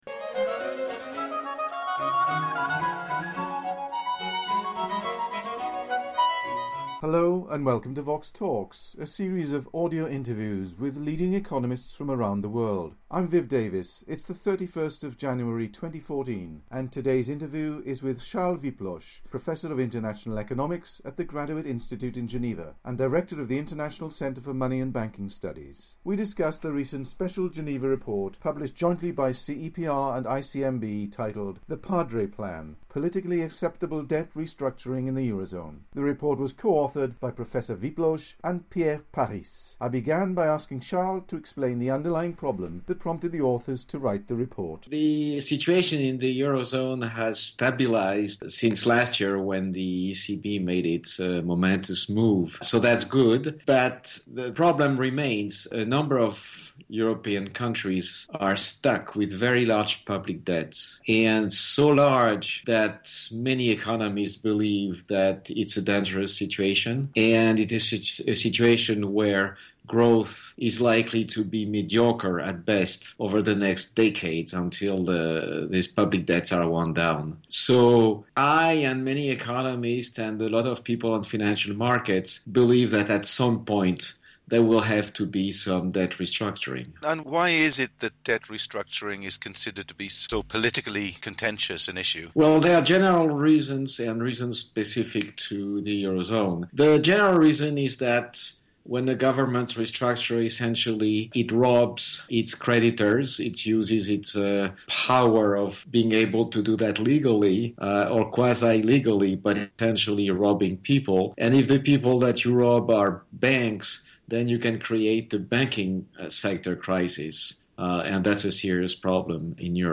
The interview was recorded in January 2014.